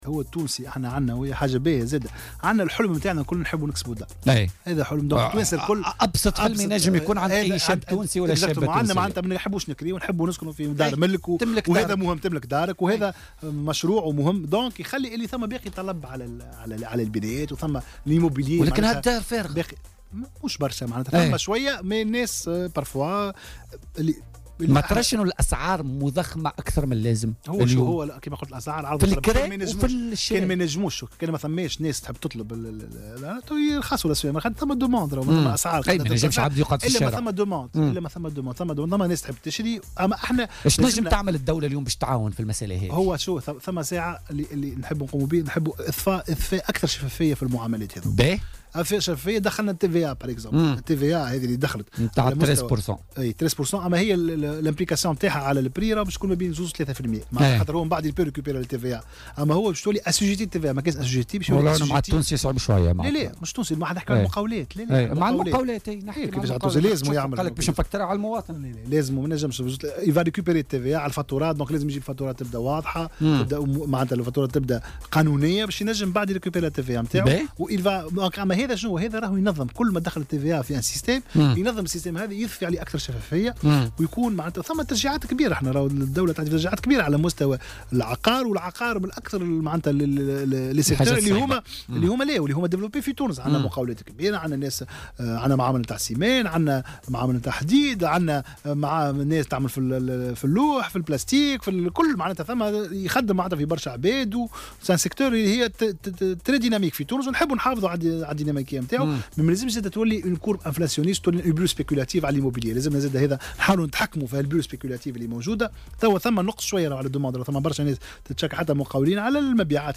قال وزير التجارة عمر الباهي ضيف "بوليتيكا" اليوم الخميس 18 جانفي 2018، إن ارتفاع أسعار المساكن، يعود إلى كثرة الطلب.